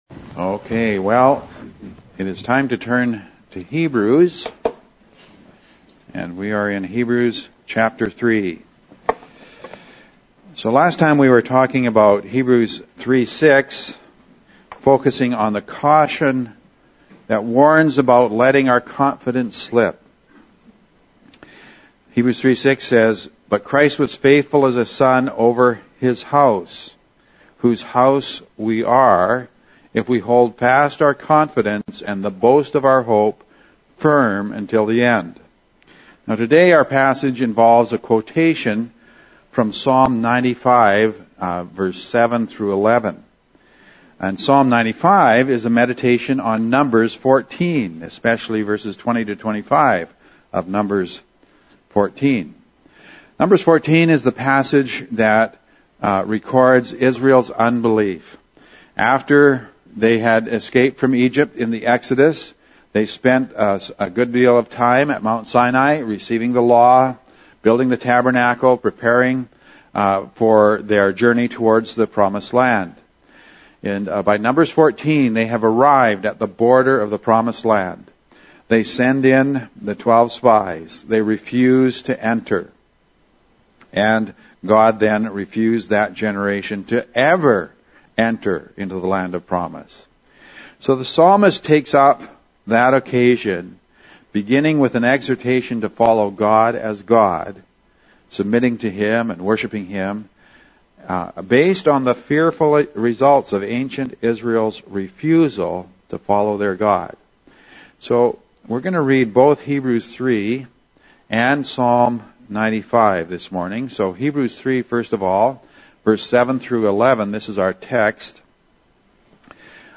PLEASE NOTE: Unfortunately, our computer crashed in the middle of Streaming/Recording this message. I added a “crumpled paper” sound effect at the point this happens… We recovered most of the message, but a couple of minutes are missing.